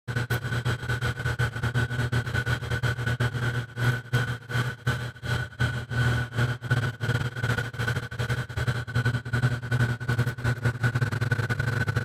fossil-fuel-burning.mp3